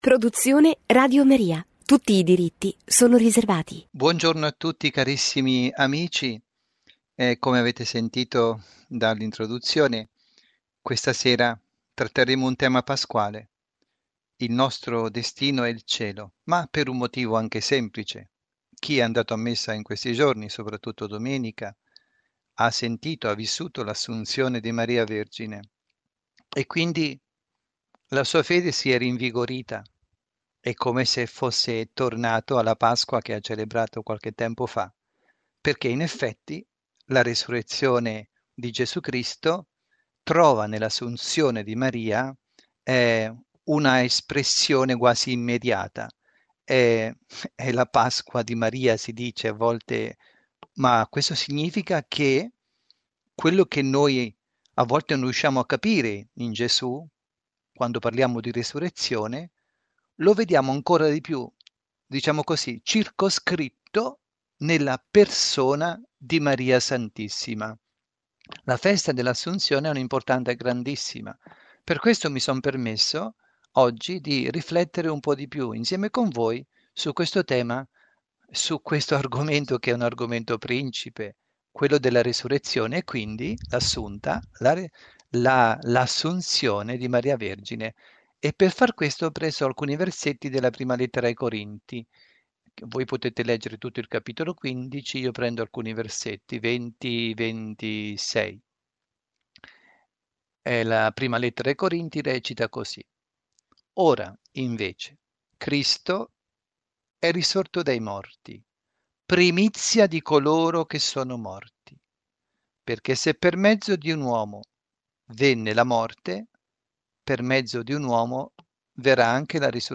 Catechesi
dalla Parrocchia Santa Rita – Milano